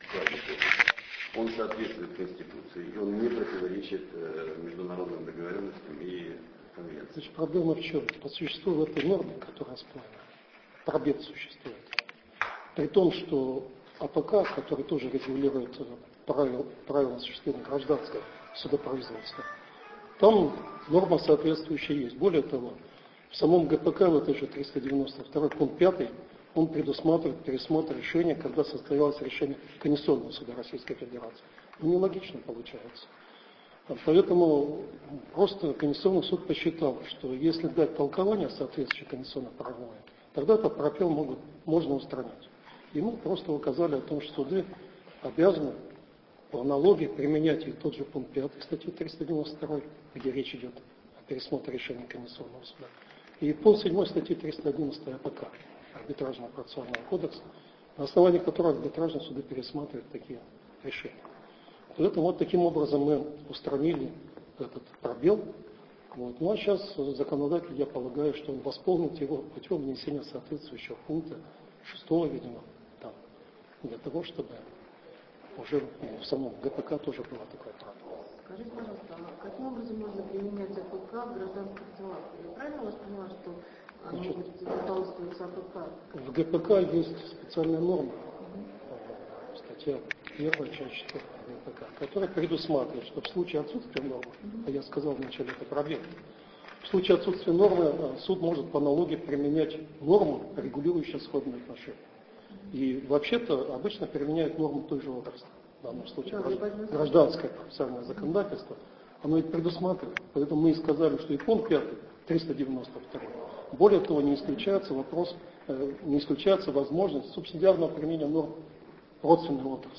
комментарий судьи Конституционного Суда РФ Геннадия Александровича Жилина о делу о проверке конституционности части второй статьи 392 Гражданского процессуального кодекса РФ: Конституционный Суд РФ постановил, что федеральному законодателю надлежит закрепить в ГПК механизм исполнения решений Европейского Суда по правам человека. .